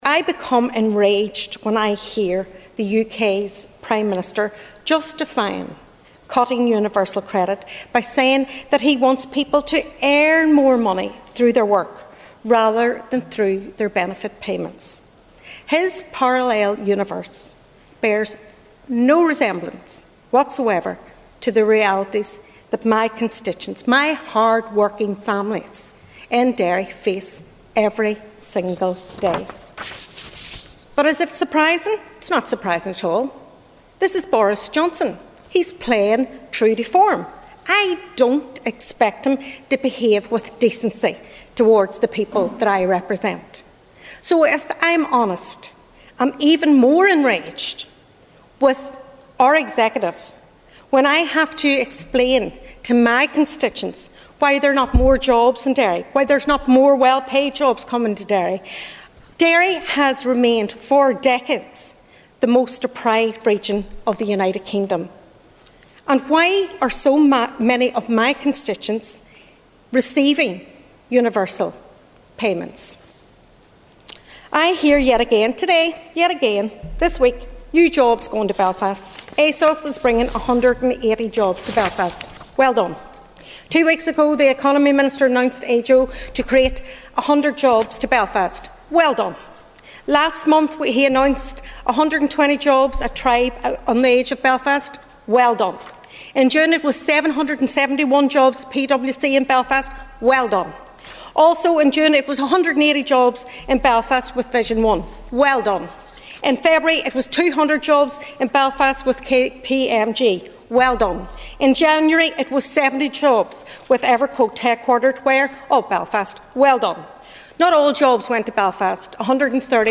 During an Assembly debate on the impact that will have in Foyle, MLA Sinead McLaughlin said the question that needs to be asked is “Why are so many families in Derry in receipt of the payment?”.